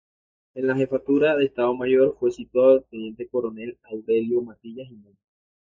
Pronounced as (IPA) /koɾoˈnel/